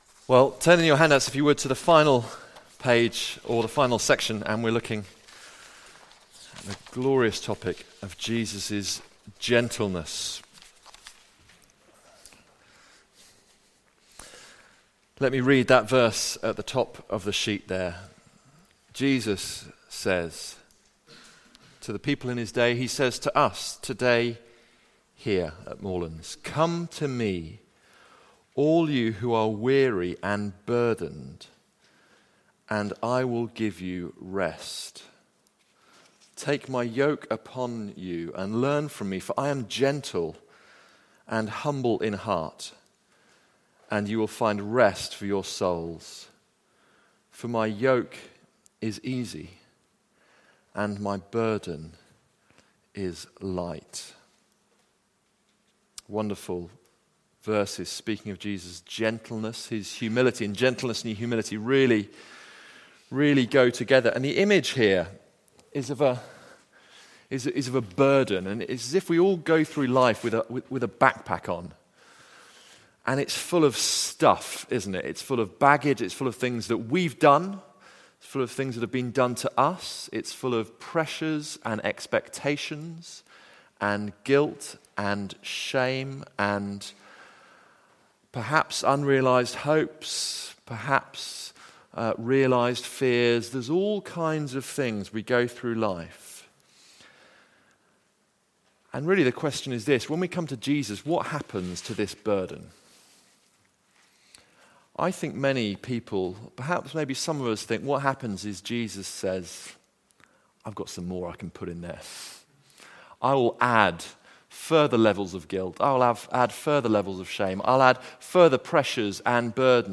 Recorded at Woodstock Road Baptist Church on 30 June 2019.